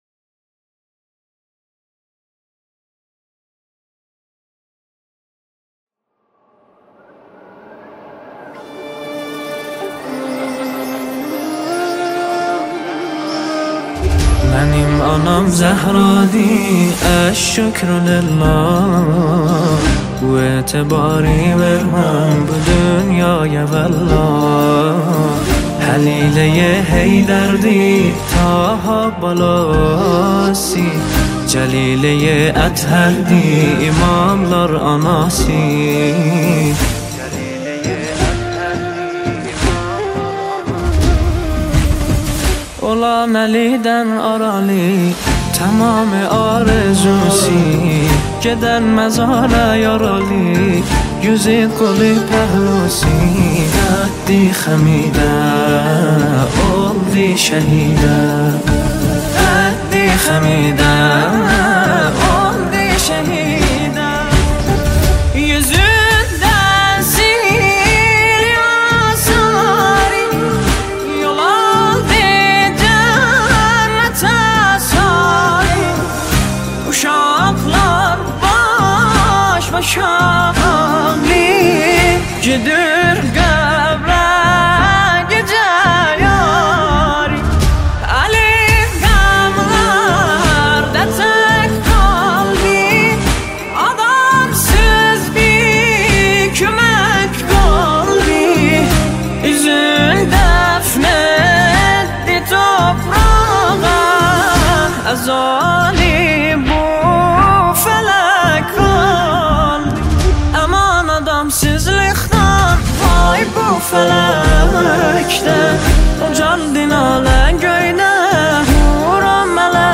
نماهنگ دلنشین ترکی، عربی وفارسی